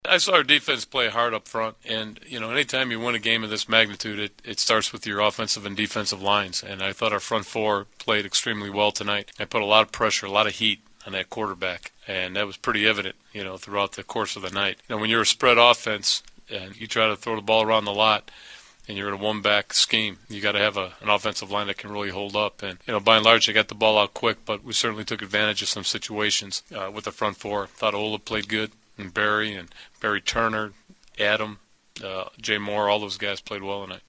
The following are audio links to postgame interview segments with Husker players and coaches after Nebraska's 56-0 win over Troy.
Head Coach Bill Callahan